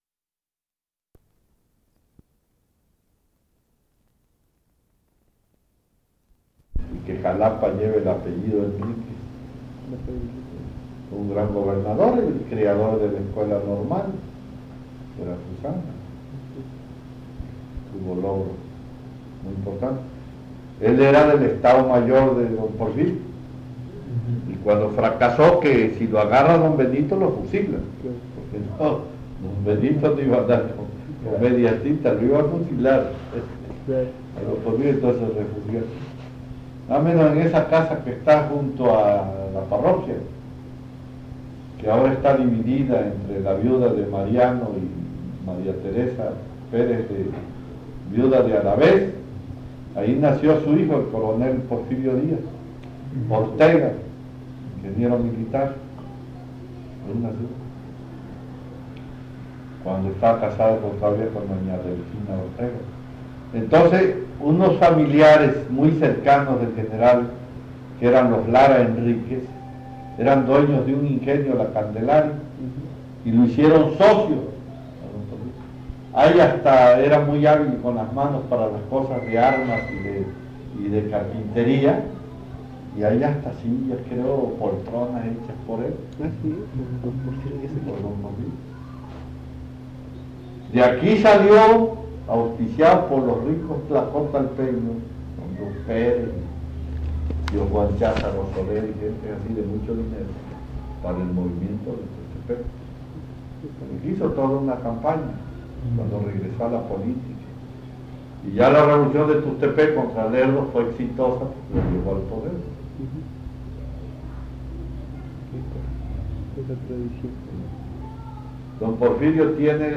Tlacotalpan, Veracruz
Entrevista